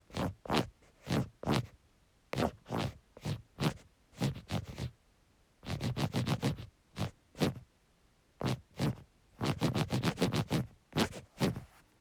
After being in bed for a few days with a serious cold, you start wondering what if I ran the phone against the bedside table?